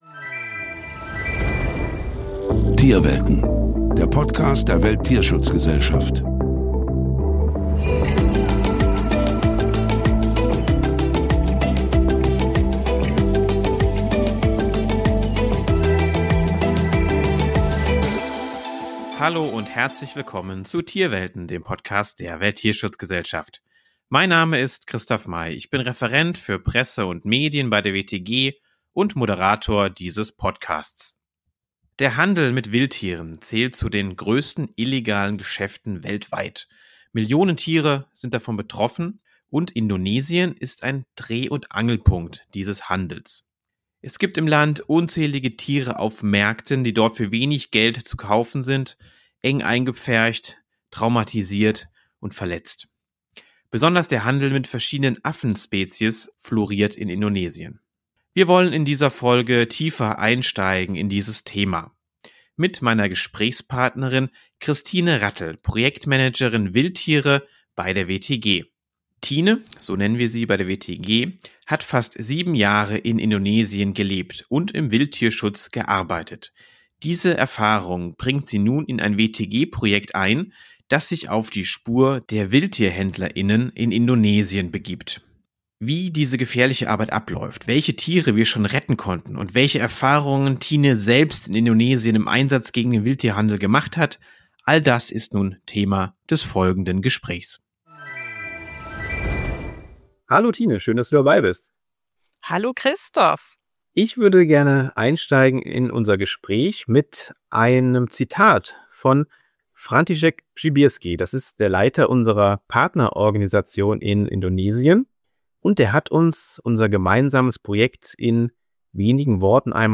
Besonders der Handel mit verschiedenen Affenspezies floriert in Indonesien, darunter auch mit Plumploris. In dieser Folge von Tierwelten sprechen wir mit einer Expertin, die den Wildtierhandel in Indonesien genau kennt.